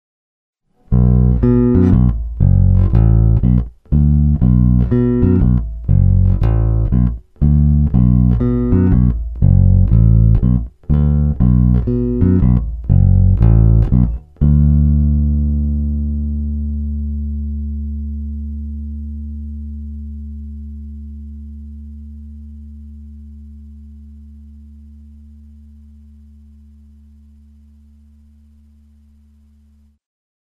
Lakland 55-02 Deluxe, struny Sandberg nehlazená ocel asi měsíc staré, aktivka zapnutá, korekce na střed, hráno prsty.
Oba snímače (blend v polovině), kobylový jako hambáč paralelně, aktivka na rovinu